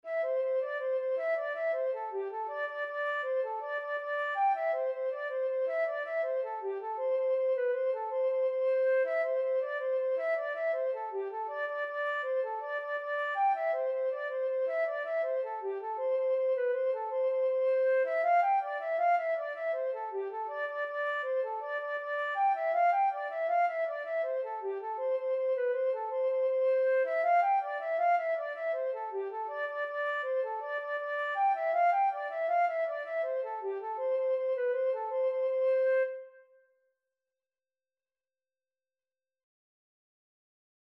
C major (Sounding Pitch) (View more C major Music for Flute )
6/8 (View more 6/8 Music)
G5-G6
Flute  (View more Easy Flute Music)
Traditional (View more Traditional Flute Music)